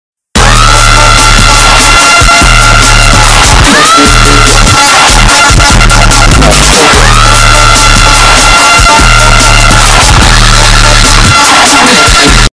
Play, download and share my cool alert tip original sound button!!!!
my-cool-alert-tip.mp3